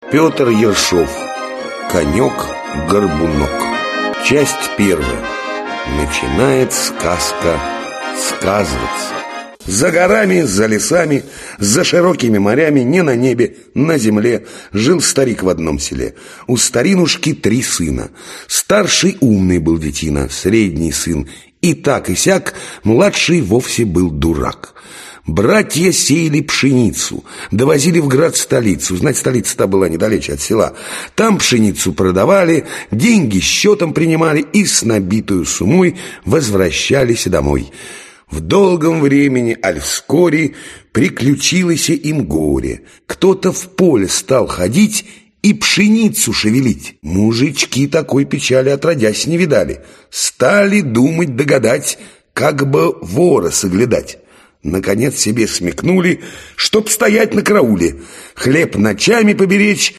Аудиокнига Конек-Горбунок | Библиотека аудиокниг
Aудиокнига Конек-Горбунок Автор Пётр Ершов Читает аудиокнигу Михаил Ефремов.